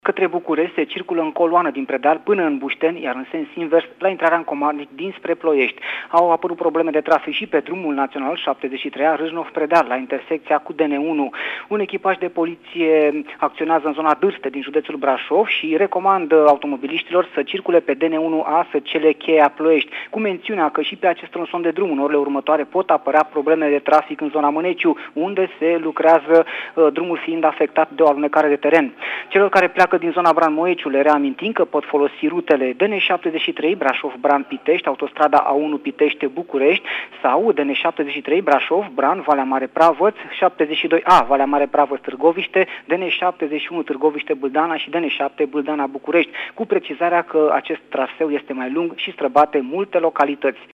Prima pagină » Raport Trafic » Trafic intens pe Valea Prahovei